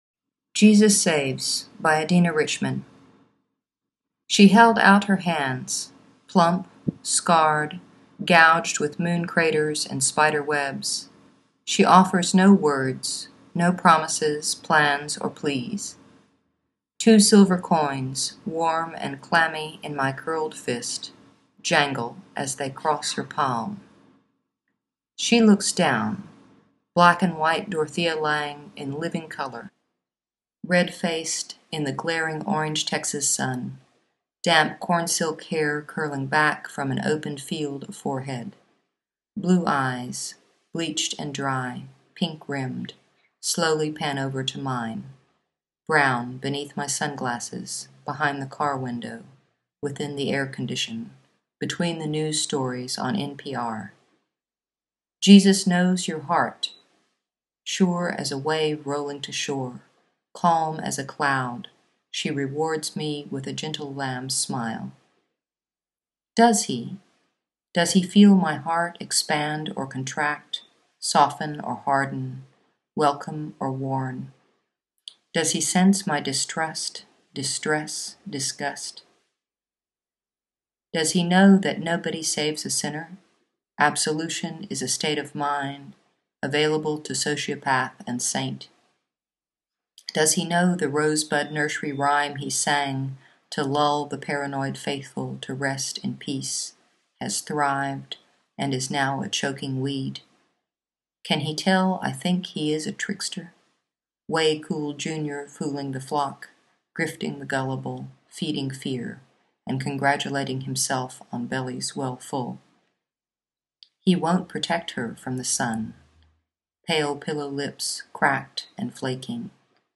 The written version of this poem has disappeared, to listen to a reading of this poem, click on the player below: